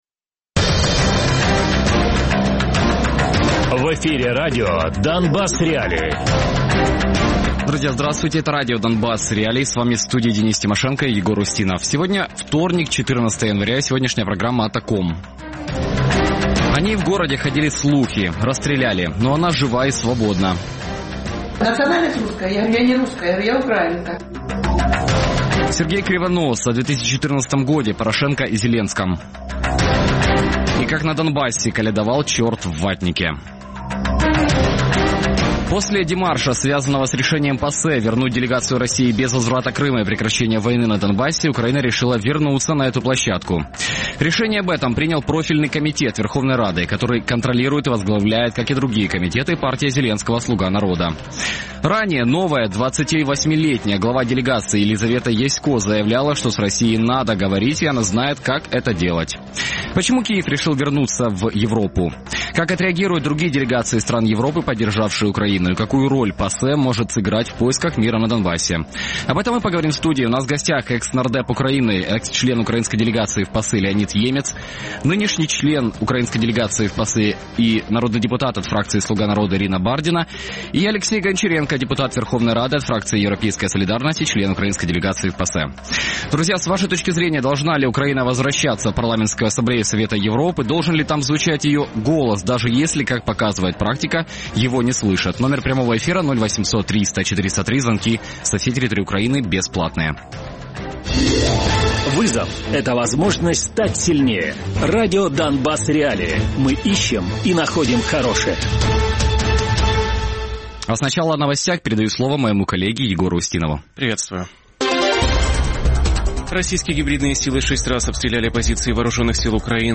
Гості: Леонід Ємець, колишній народний депутат України, екс-член української делегації у ПАРЄ, Марина Бардіна - народна депутатка України із фракції «Слуга народу», член української делегації у ПАРЄ, Олексій Гончаренко - депутат Верховної Ради (фракція «Європейська солідарність»), член української делегації у ПАРЄ. Радіопрограма «Донбас.Реалії» - у будні з 17:00 до 18:00. Без агресії і перебільшення. 60 хвилин про найважливіше для Донецької і Луганської областей.